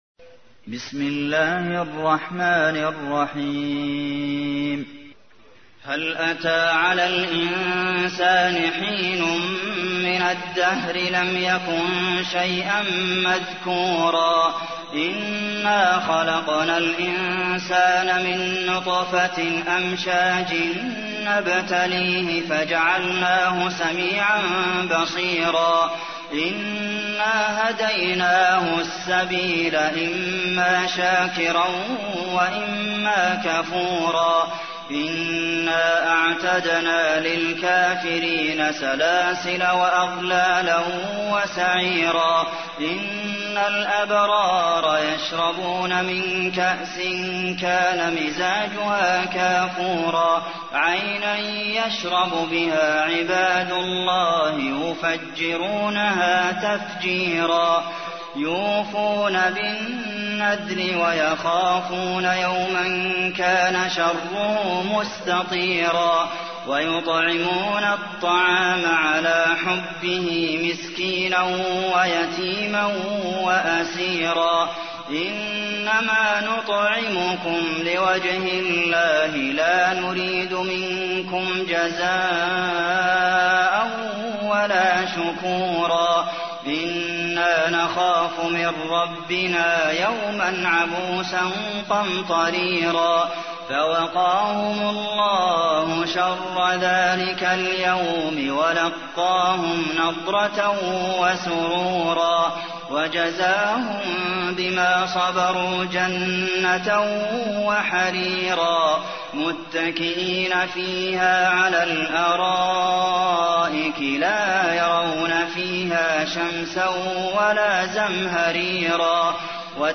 تحميل : 76. سورة الإنسان / القارئ عبد المحسن قاسم / القرآن الكريم / موقع يا حسين
موقع يا حسين : القرآن الكريم 76.